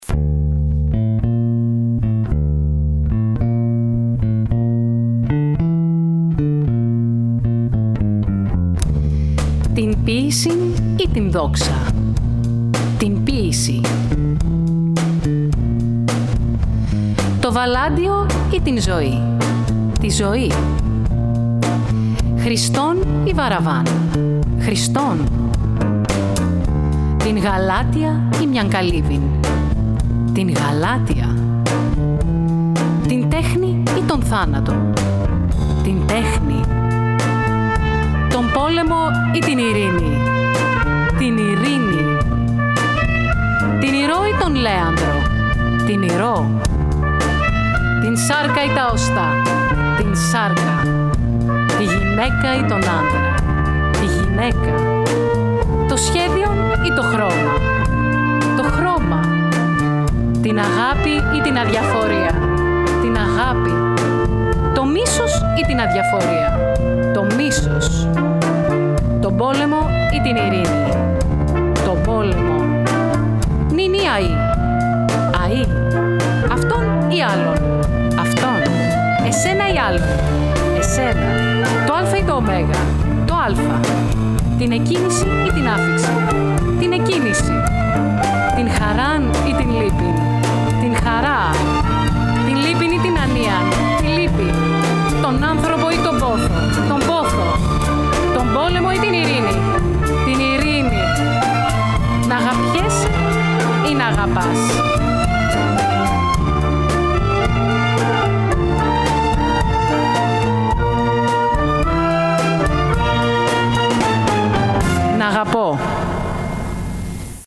Ηχογράφηση στο STUDIO B, Παρασκευή 16 Οκτωβρίου 2020
Στο πιάνο ο συνθέτης